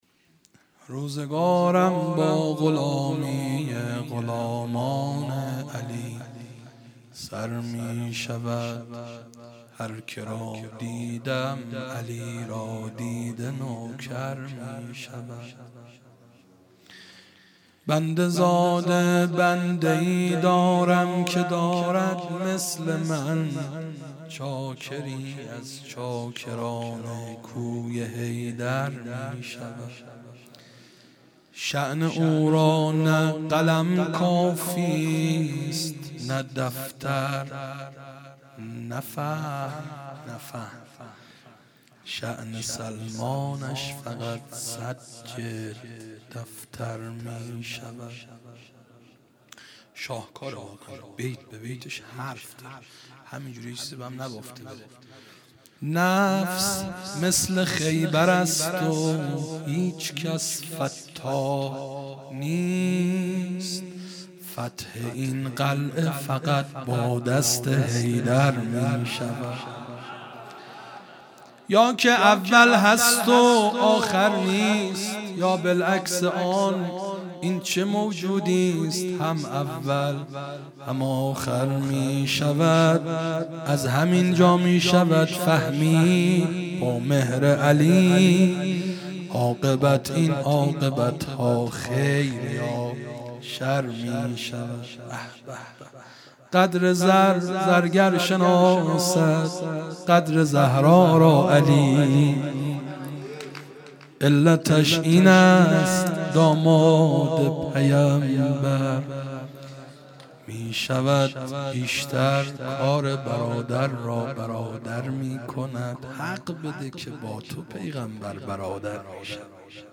مدح
آغاز امامت امام زمان (عج) | ۷ آذر ۹۶